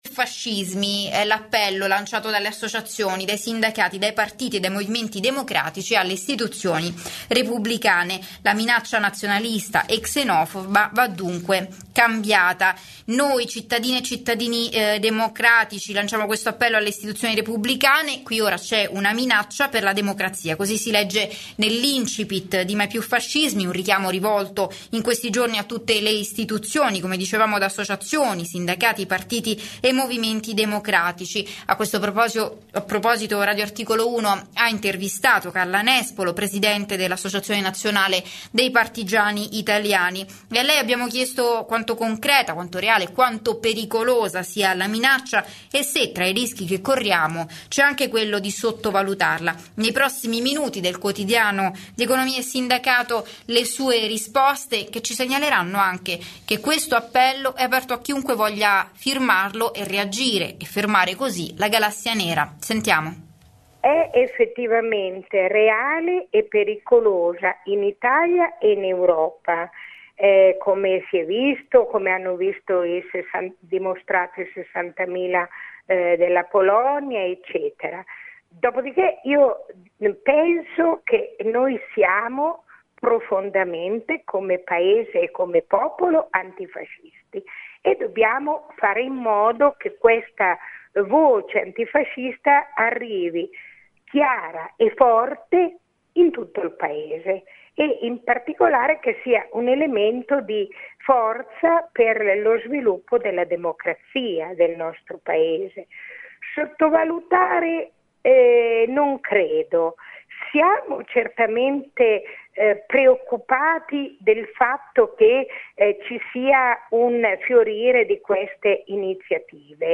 La Presidente Nazionale ANPI, Carla Nespolo, intervistata da Radio Articolo 1, l'emittente della CGIL Nazionale